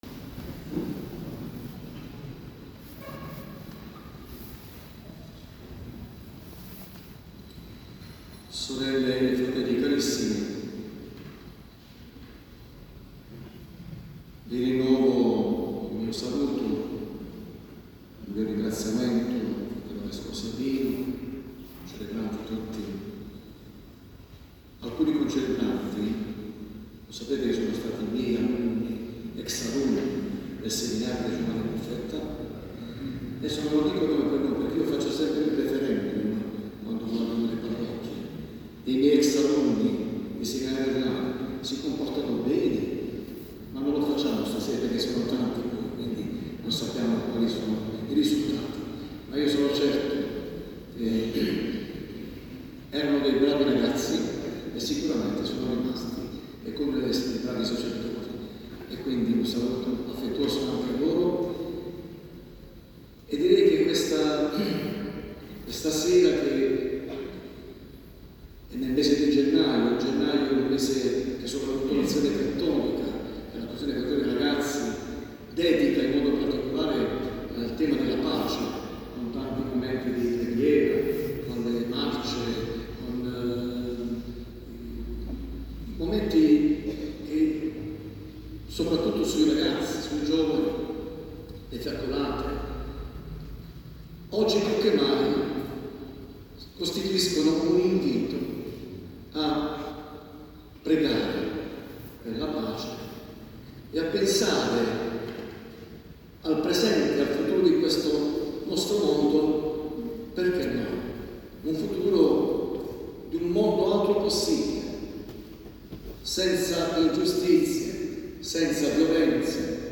L'omelia di Mons. Giovanni Ricchiuti, Presidente di Pax Christi Italia, durante la Santa Messa per la Pace presso la Parrocchia Sacro Cuore di Gesù in Massafra.
Omelia-Vescovo-Ricchiuti-messa-pace-22-1-26.mp3